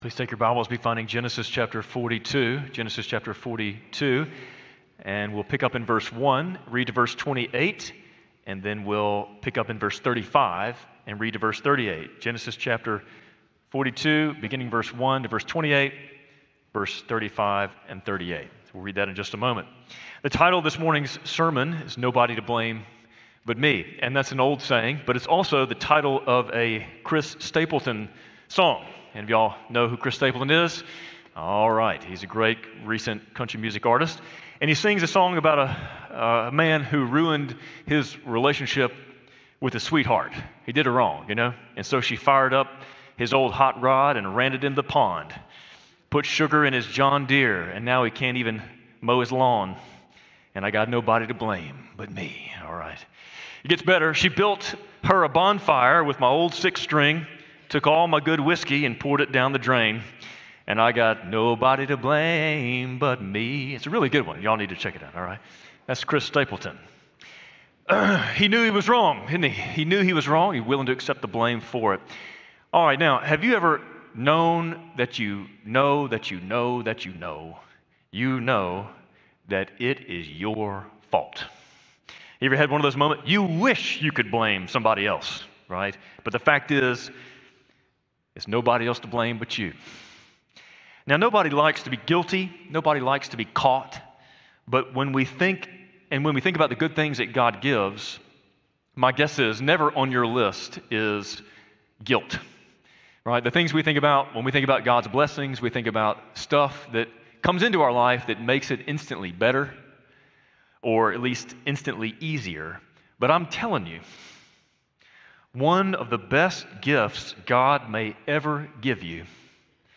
Spring Hill Baptist Sunday Sermons (Audio) / Nobody To Blame But Me